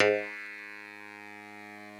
genesis_bass_032.wav